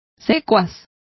Complete with pronunciation of the translation of underlings.